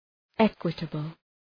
Προφορά
{‘ekwıtəbəl}
equitable.mp3